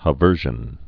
(hə-vûrzhən)